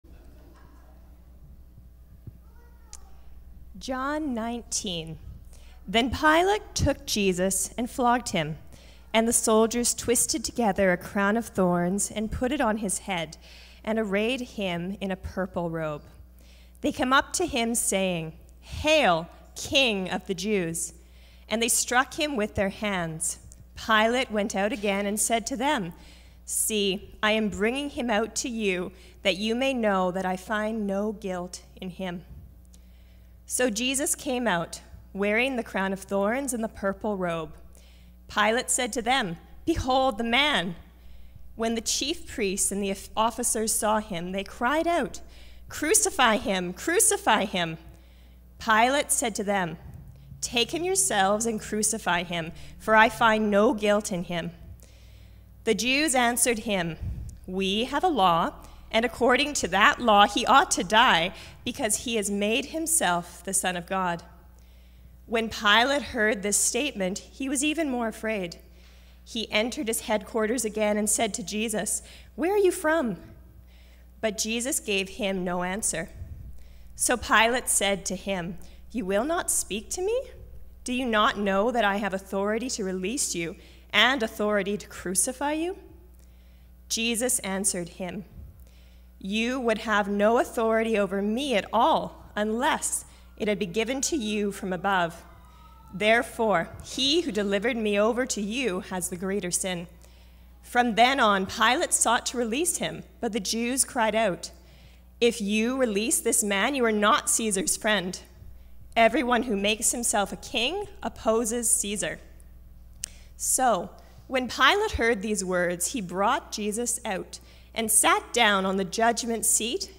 Cascades Church Sermons The Suffering of the King Play Episode Pause Episode Mute/Unmute Episode Rewind 10 Seconds 1x Fast Forward 30 seconds 00:00 / 21:25 Subscribe Share Apple Podcasts RSS Feed Share Link Embed